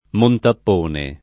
[ montapp 1 ne ]